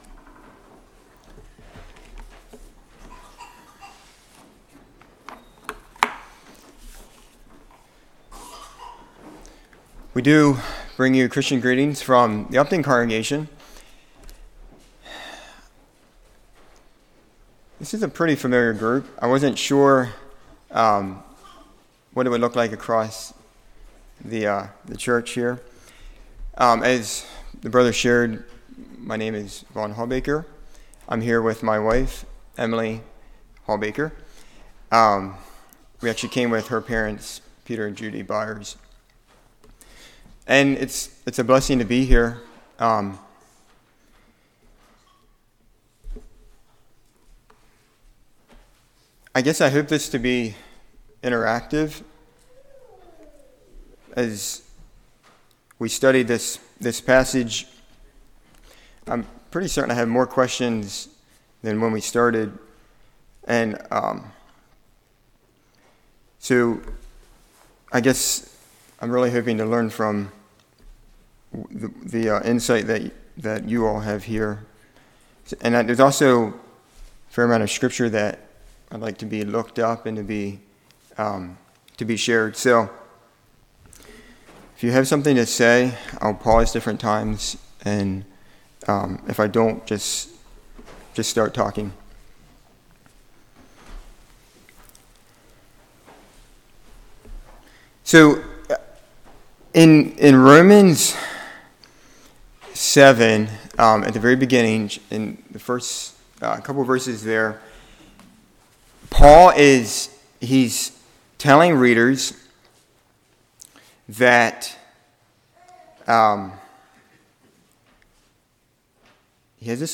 Series: Fall Revival 2023
Service Type: Sunday School